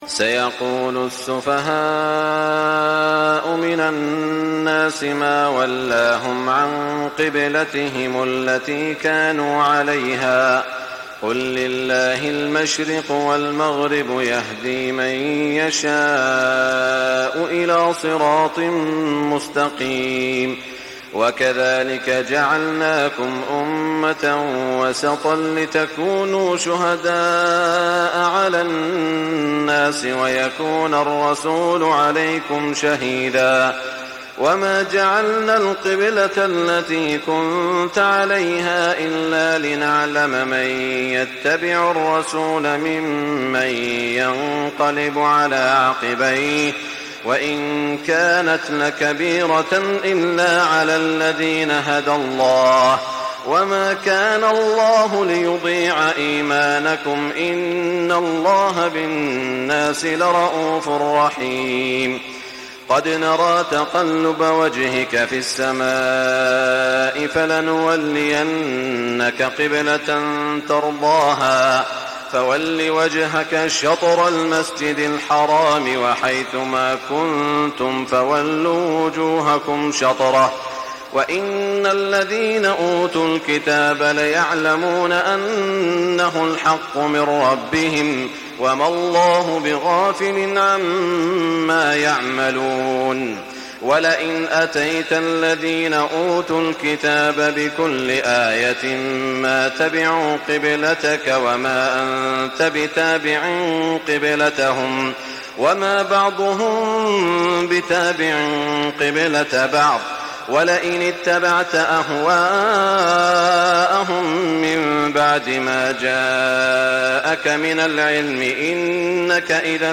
تهجد ليلة 22 رمضان 1420هـ من سورة البقرة (142-217) Tahajjud 22 st night Ramadan 1420H from Surah Al-Baqara > تراويح الحرم المكي عام 1420 🕋 > التراويح - تلاوات الحرمين